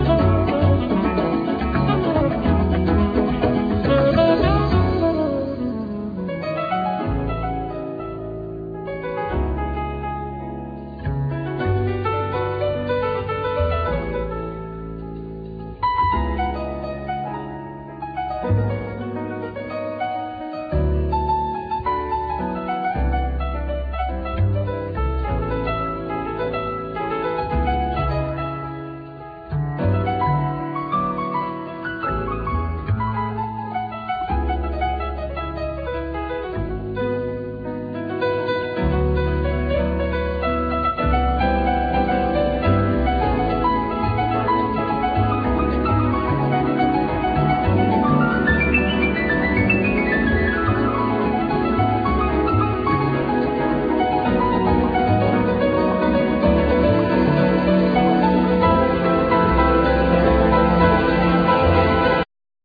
Vocal,Alto saxophone
Ac.guitar,Mandolin
Bouzuki,Bodhran,Coros
Tenor&Soprano saxophone,Synthesizer
Contra-bass,El.bass
Piano,Synthesizer
Drums,Percussions
Trumpet
Irish harp
Irish gaita,Flute